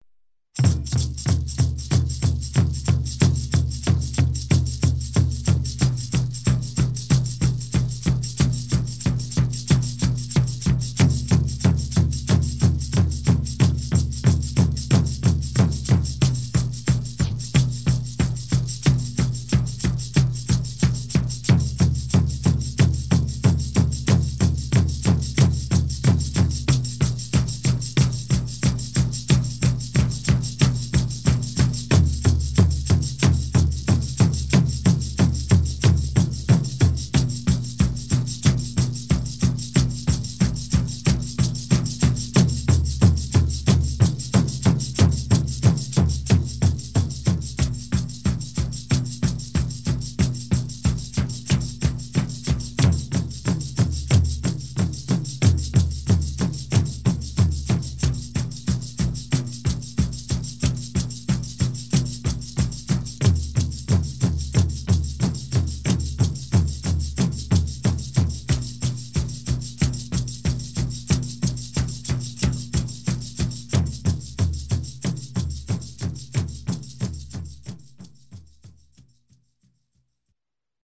Ритуал с бубном и тамбурином